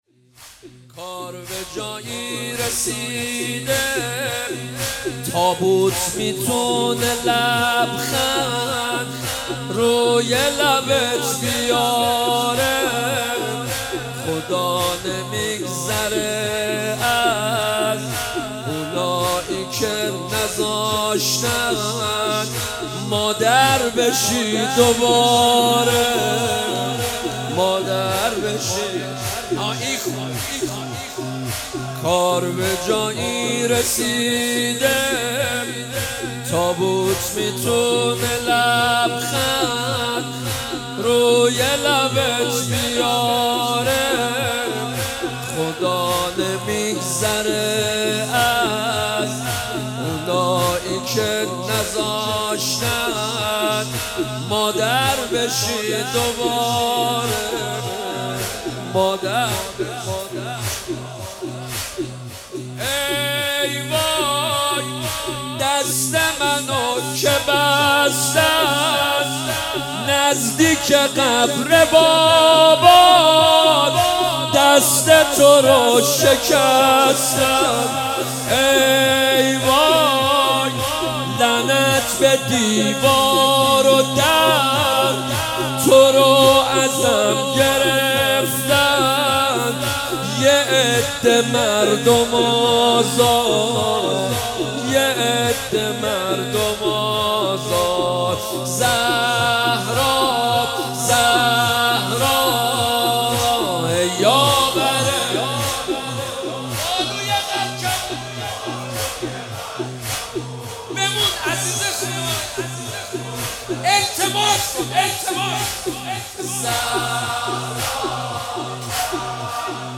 مداحی
روضه